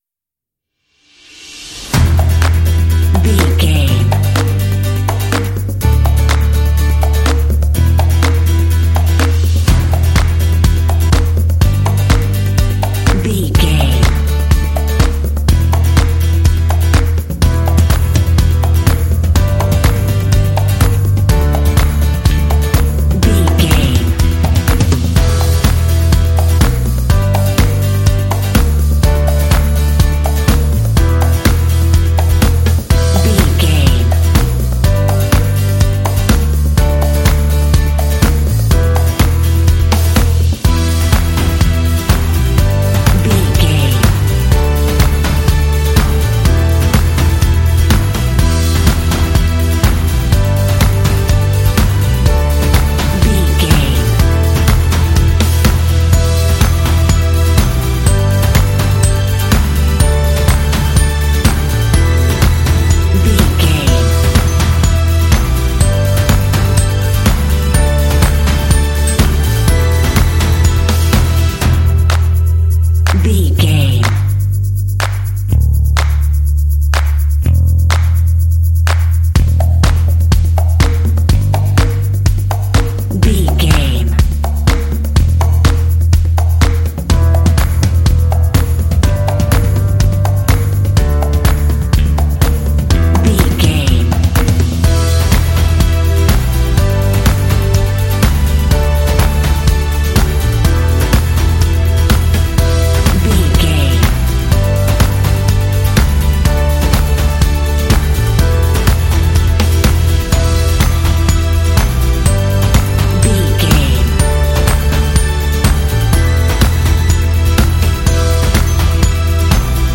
Uplifting
Aeolian/Minor
lively
cheerful
acoustic guitar
conga
percussion
drums
bass guitar
strings
synth-pop
rock
indie